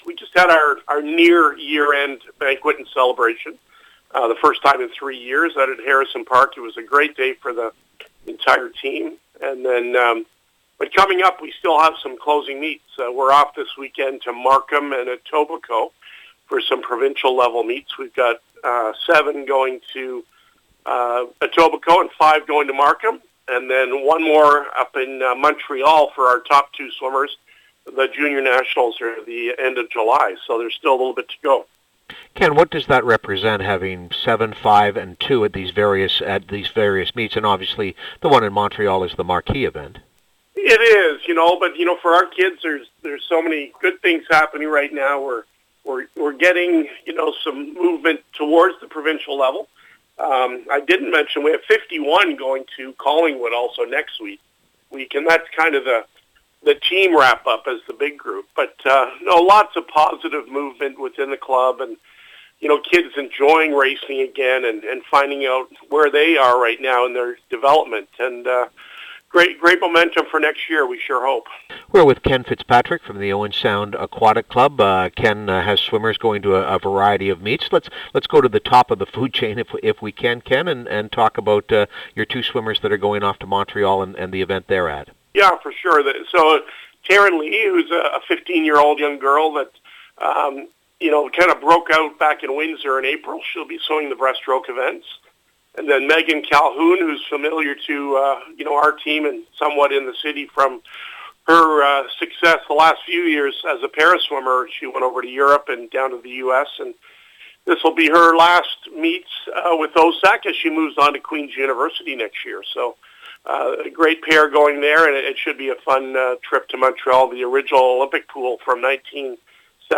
Swim Season Climax. Interview